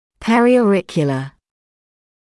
[ˌperɪɔː’rɪkjələl][ˌпэриоː’рикйэлэ]околоушный